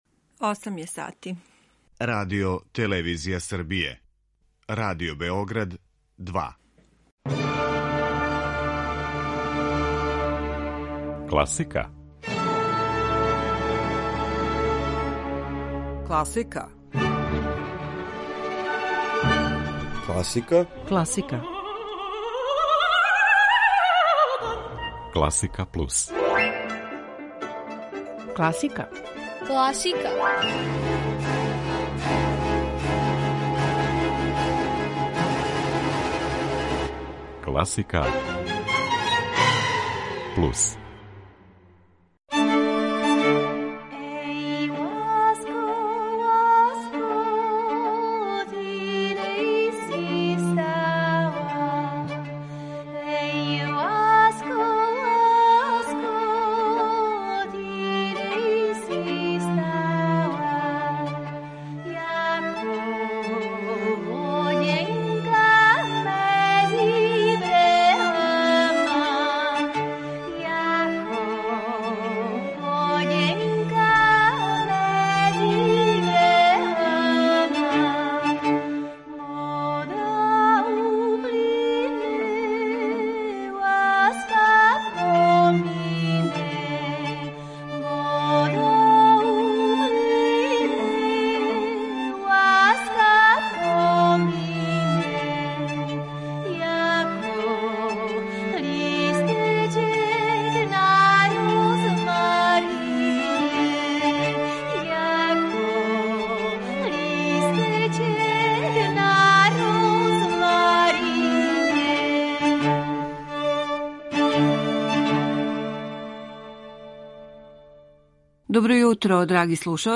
Емисија класичне музике
Слушаћете аутентичне интерпретације Иве Битове, Сабине Мејер, Иве Погорелића, хор и оркестар Француске радио-дифузије и Еиотора Виља-Лобоса, који диригује извођењем једног свог дела.